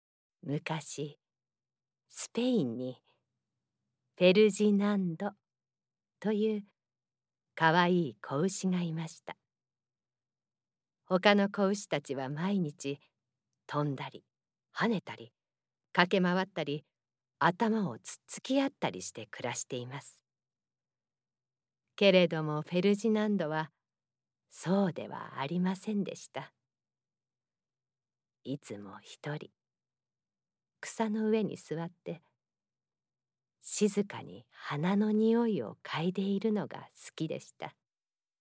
ナレーションA↓